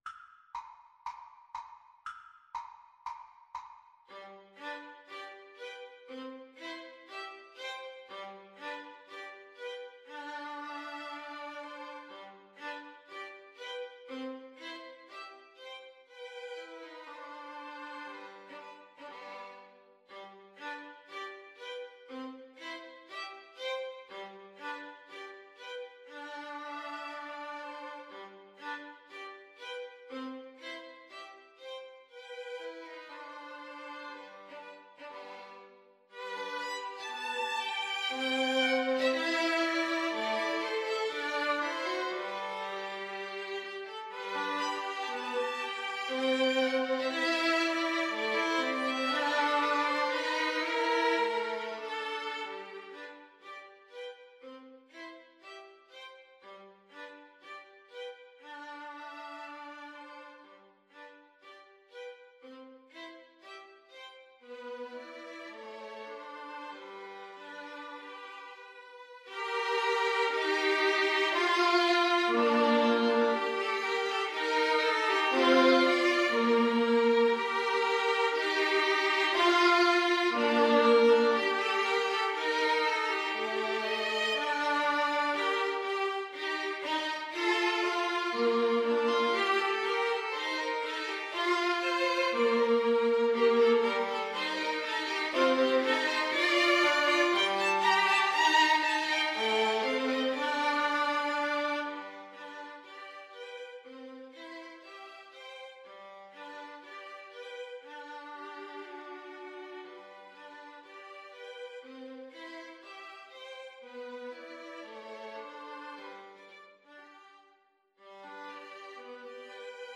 Classical Dvořák, Antonín Humoresque Op. 101, No. 7 Violin Trio version
G major (Sounding Pitch) (View more G major Music for Violin Trio )
= 60 Poco lento e grazioso